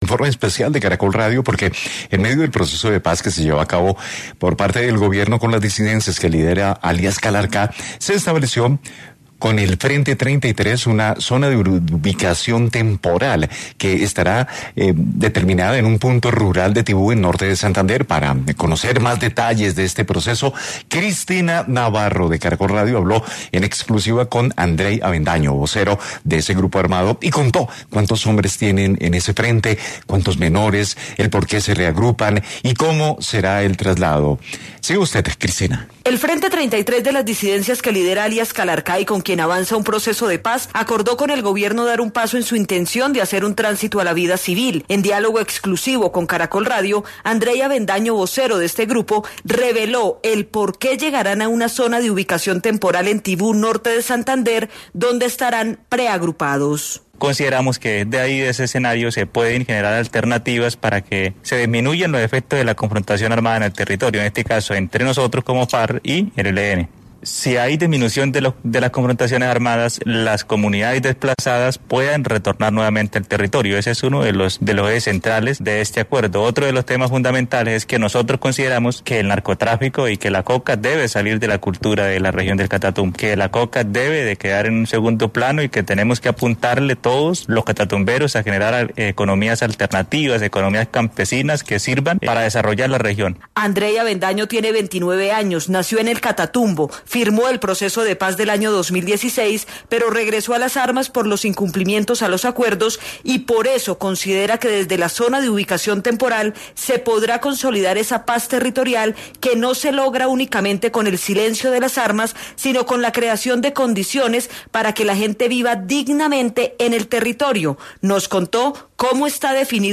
Caracol Radio llegó hasta la zona y conoció cuántos hombres tiene este frente y cuántos de ellos son menores de edad. Reportaje exclusivo.